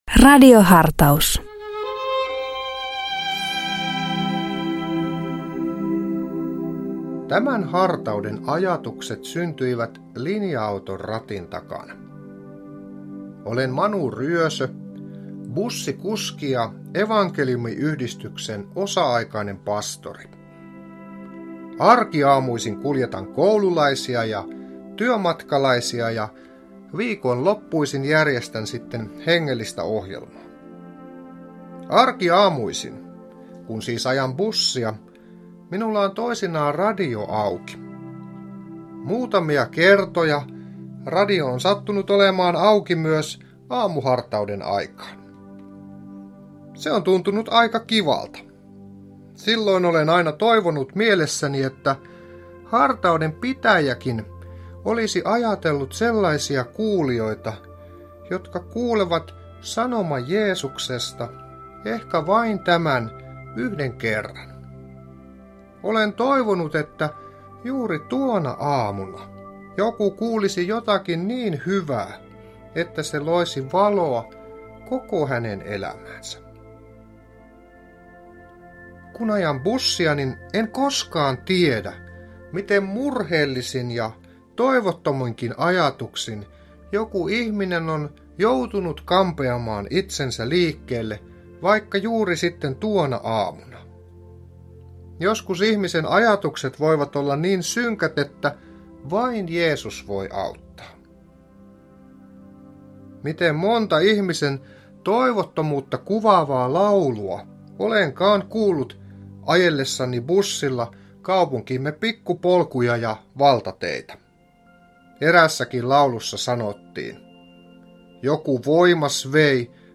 Radio Dei lähettää FM-taajuuksillaan radiohartauden joka arkiaamu kello 7.50. Radiohartaus kuullaan uusintana iltapäivällä kello 17.05.
Radio Dein radiohartauksien pitäjinä kuullaan laajaa kirjoa kirkon työntekijöitä sekä maallikoita, jotka tuntevat radioilmaisun omakseen.